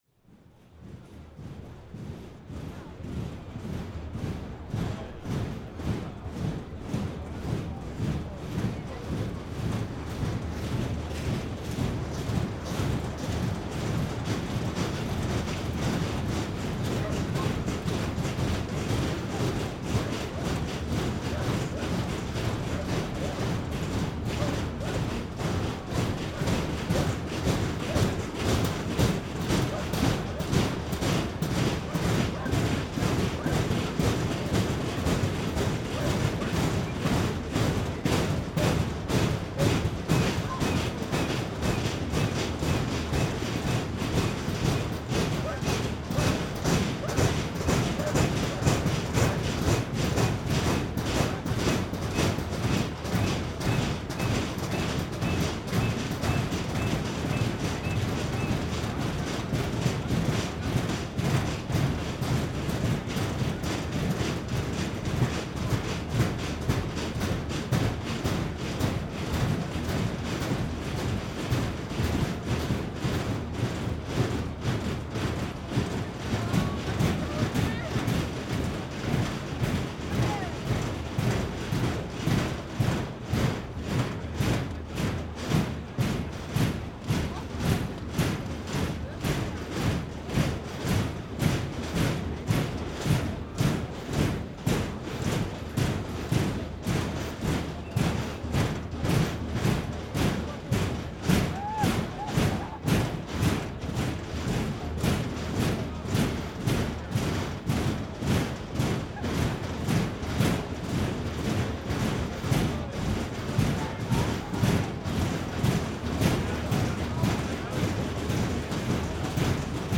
Around 4,000 people turned up to Austurvöllur square in front of the parliament 24th of February to protest the decision by the ruling Progressive and Independence Parties to put forward a resolution to withdraw from European Union talks. Earlier this day police fenced off the parliament building with aluminum riot fence.
This time police brought the instrument, and the protesters played and kicked the fence.
Meðfylgjandi upptaka var tekin upp á fyrsta degi mótmæla norðan við dómkirkjuna rétt fyrir kl. 16.
Recorder: Sound devices 744 Mics: Sennheiser 8040 (ORTF setup)
t585_protest-in-februar.mp3